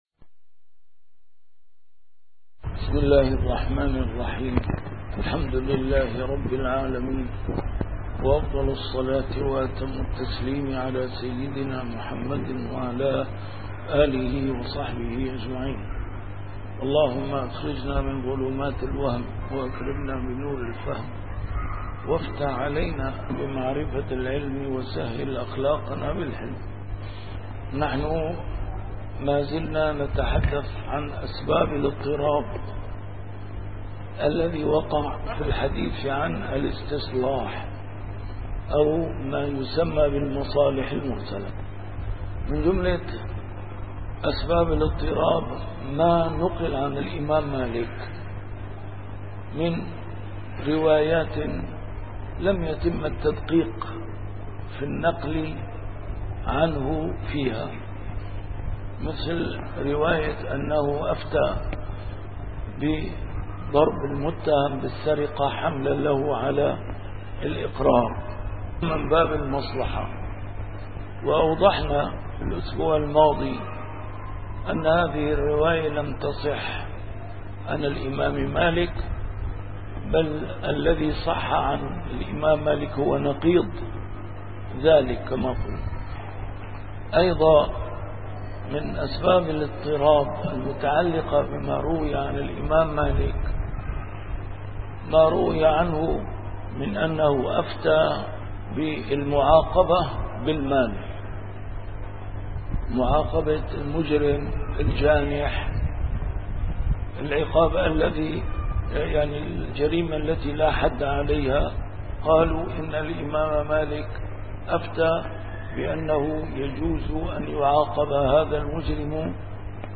A MARTYR SCHOLAR: IMAM MUHAMMAD SAEED RAMADAN AL-BOUTI - الدروس العلمية - ضوابط المصلحة في الشريعة الإسلامية - أسباب الاضطراب: السبب الثاني (350-352) السبب الثالث (352)صفوة القول: المصالح المرسلة مقبولة بالاتفاق (354-357)